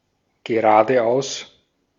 Ääntäminen
IPA : /stɹeɪt/